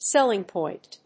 アクセント・音節séll・ing pòint